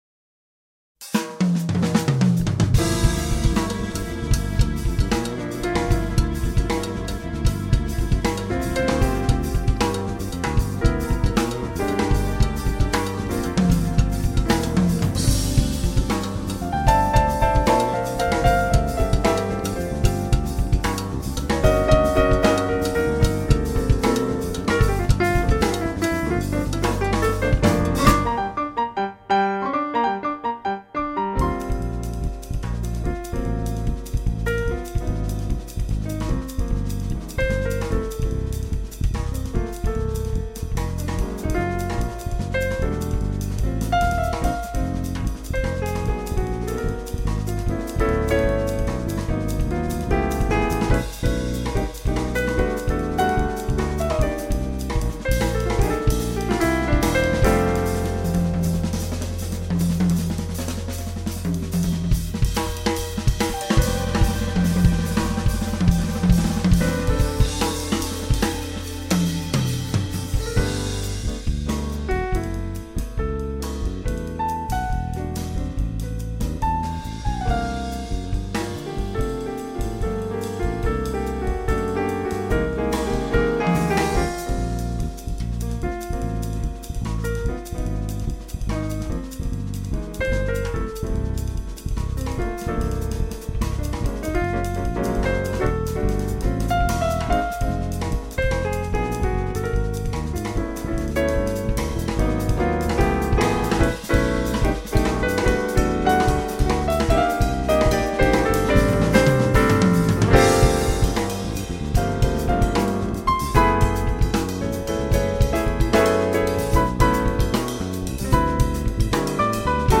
keyboads
bass
drums